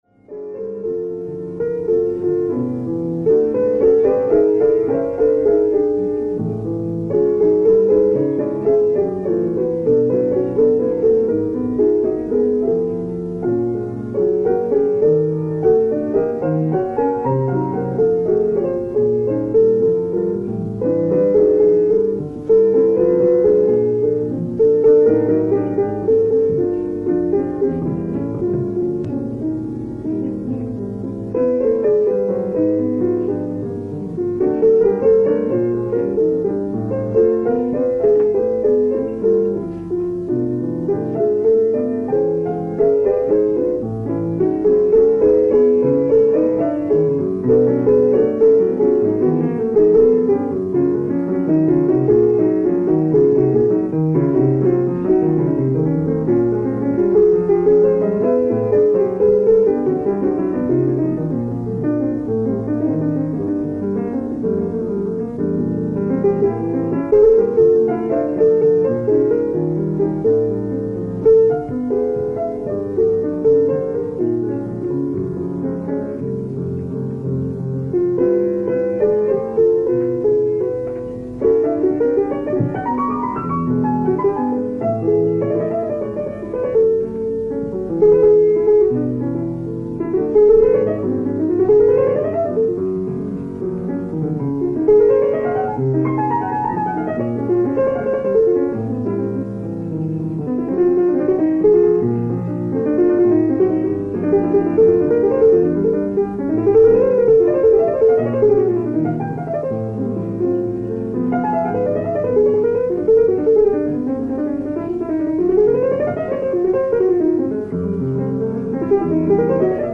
ライブ・アット・アポロ・シアター、パリ、フランス 05/24/1970
※試聴用に実際より音質を落としています。